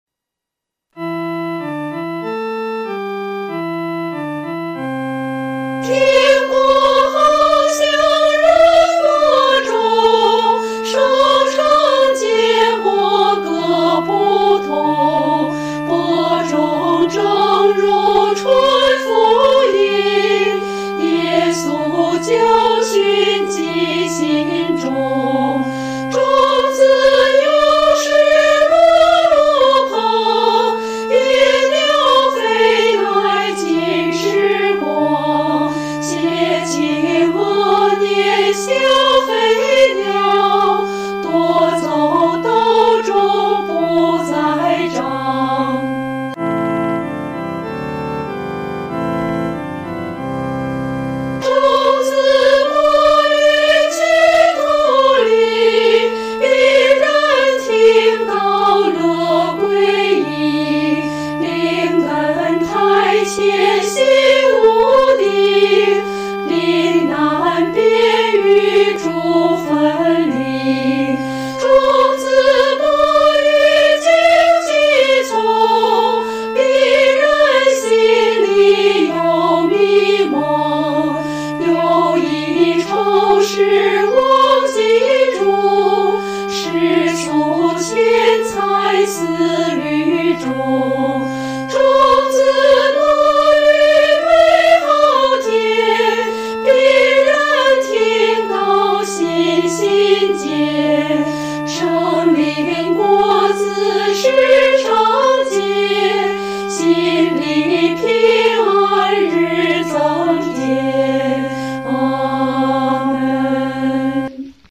合唱
女高
所用曲谱是在河北省一带于解放前所流行的民歌，曾被配上多种歌词。